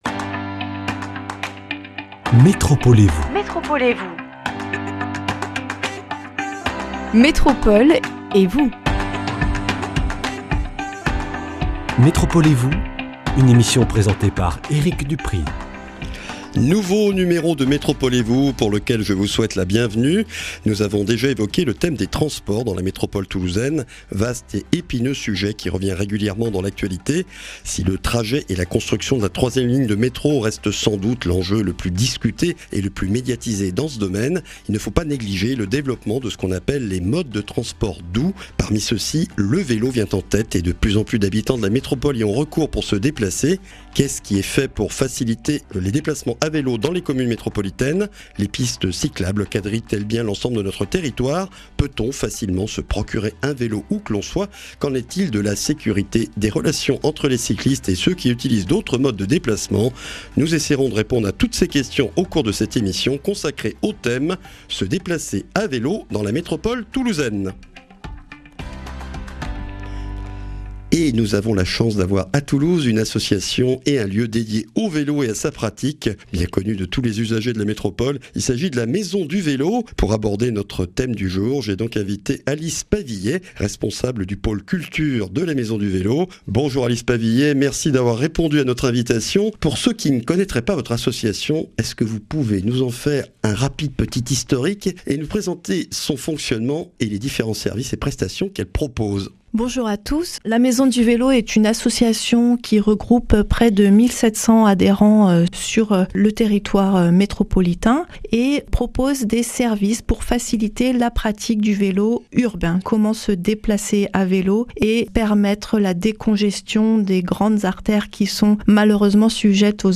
Une émission